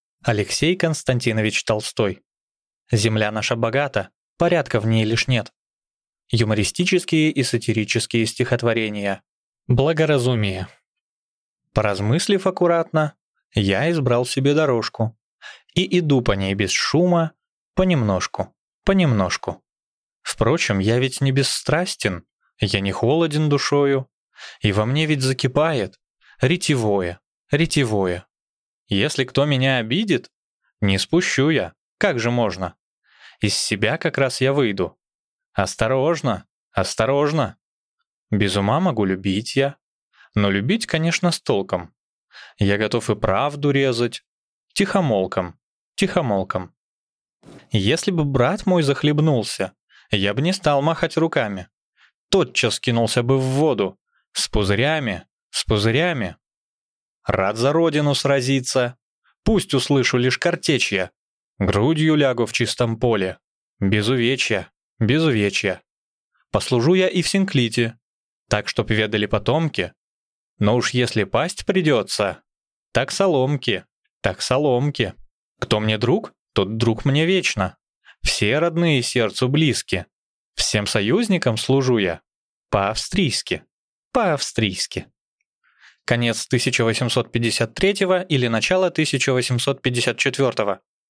Аудиокнига Земля наша богата, порядка в ней лишь нет… (сборник) | Библиотека аудиокниг